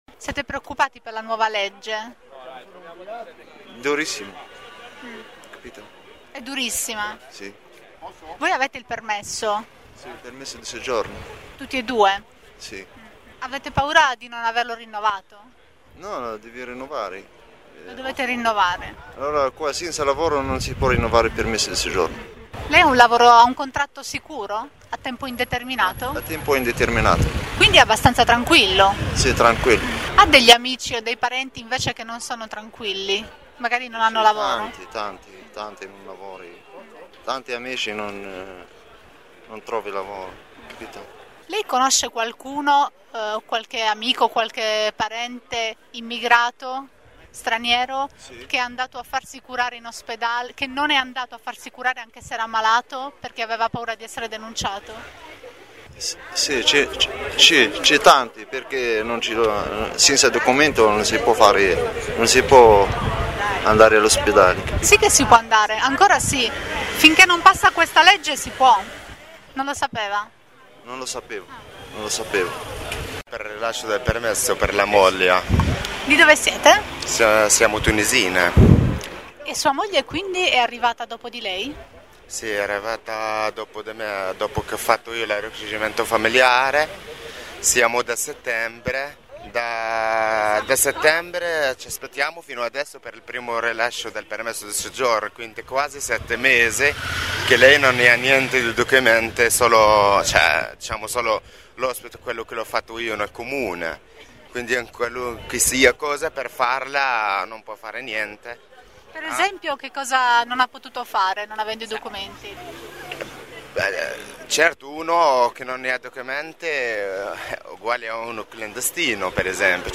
Durante il presidio abbiamo intervistato alcuni cittadini stranieri che aspettavano fuori dal palazzo del governo per consegnare alcuni documenti: una coppia marocchina, una coppia tunisina e una famiglia pakistana, tutti molto giovani